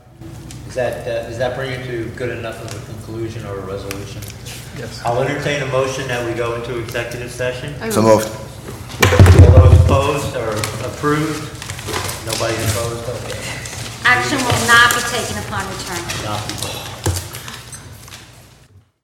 Read the minutes from the July 25, 2011 school board meeting, then listen to the recording of the motion to go to executive session.